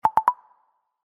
Spotify Play Sound Effect Free Download